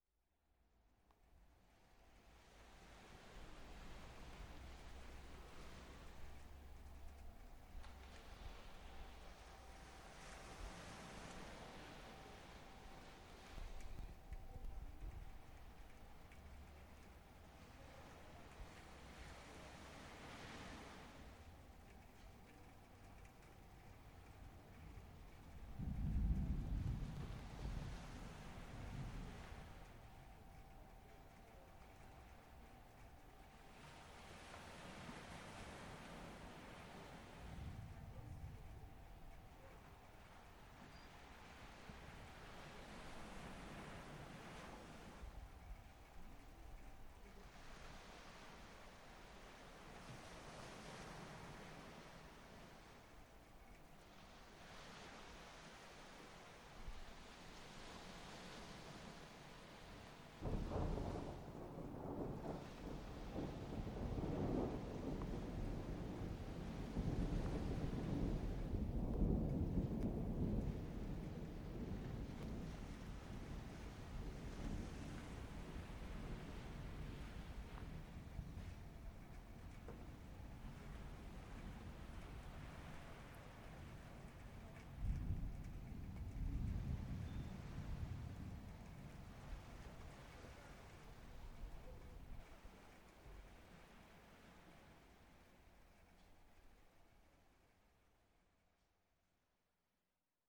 J'utilise un matos d'amateur mais suffisamment sérieux pour obtenir d'excellents résultats : enregistreur MD digital Sony MZ-R70, micro stéréo Sony ECM-MS907, casque DJ Sony MDR-V 500.
L'orage gronde en bord de mer
Ce son à été enregistré depuis un balcon avec vue sur la mer - les "poc - poc" que vous entendez par intermittence sont les grosses gouttes d'eau qui s'écrasent sur la rambarde, annonciatrices d'une pluie battante. Et puis il y a l'orage qui gronde au dessus de la mer...
orage.mp3